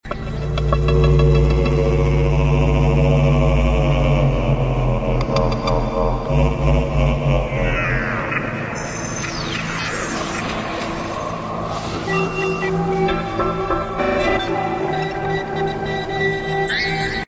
The sound design was composed by myself to complement this short video loop.